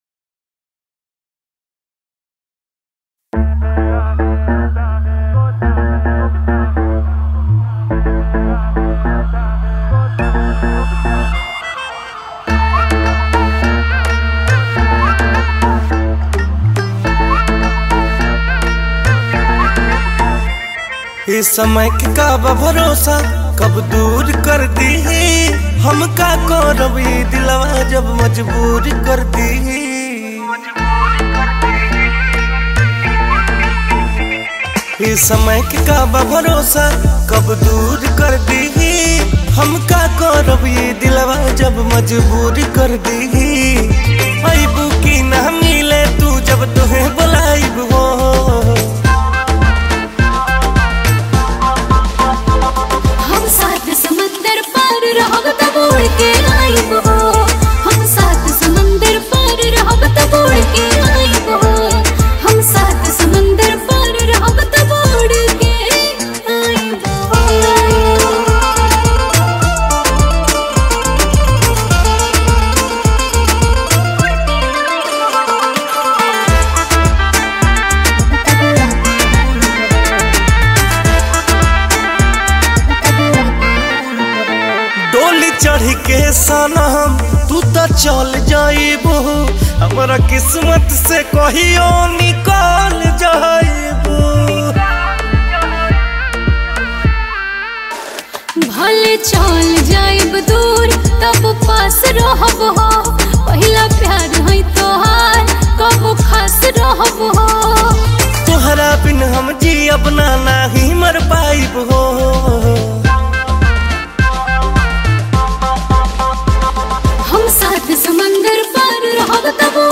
Releted Files Of Bhojpuri Mp3 Song